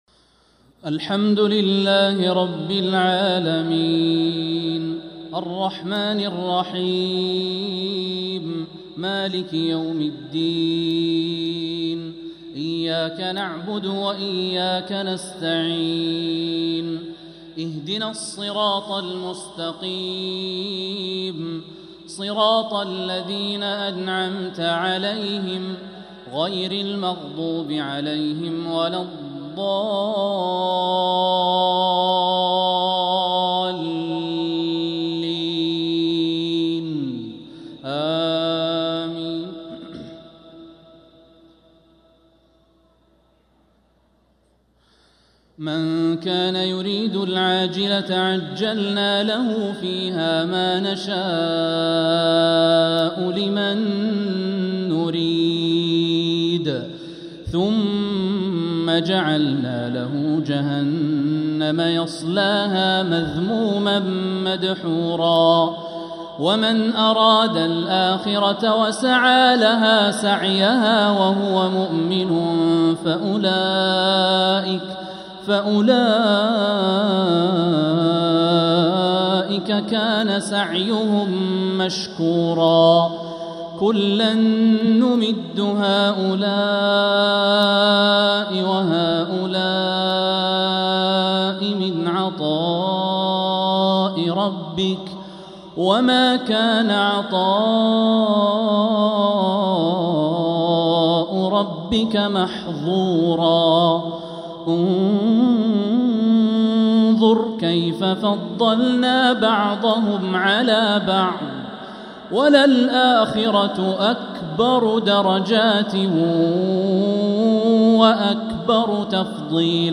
عشاء الأحد 5-7-1446هـ من سورة الإسراء 18-25 | isha prayer from Surat Al-Isra 5-1-2025 🎙 > 1446 🕋 > الفروض - تلاوات الحرمين